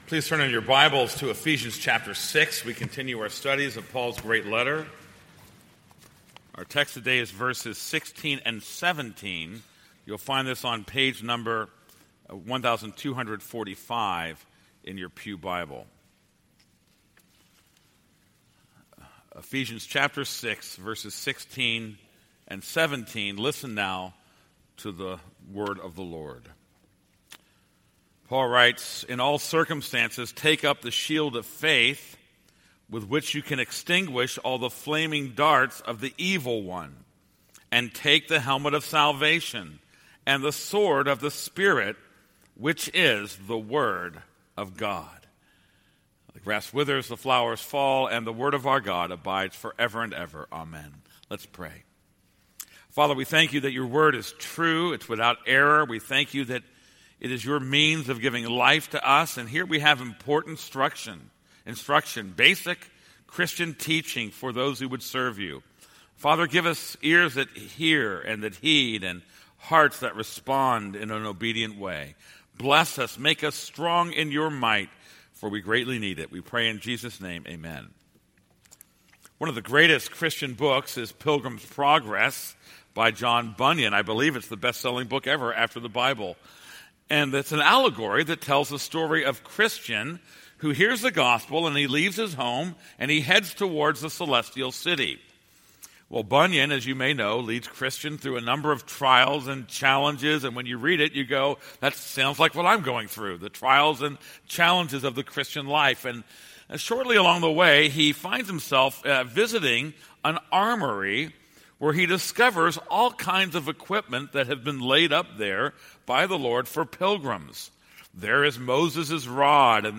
This is a sermon on Ephesians 6:16-17.